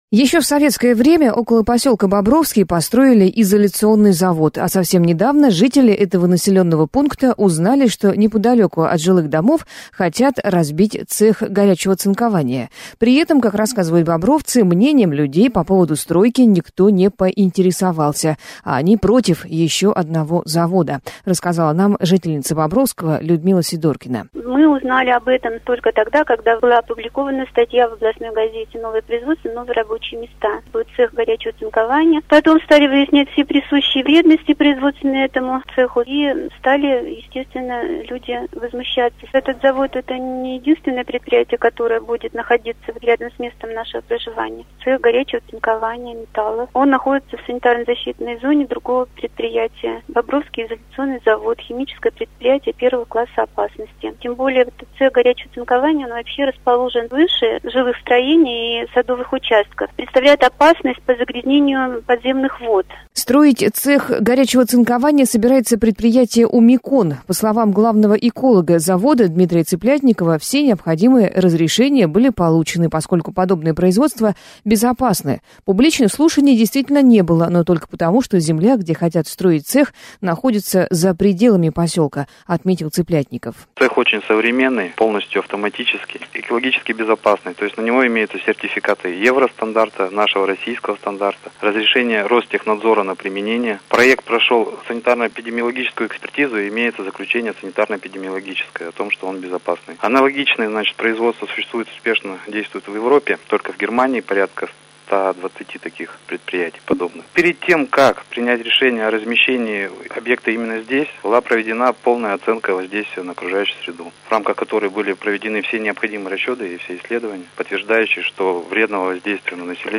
Жители Бобровского протестуют против строительства завода - репортаж